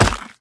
sounds / mob / goat / impact1.ogg
impact1.ogg